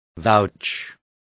Complete with pronunciation of the translation of vouch.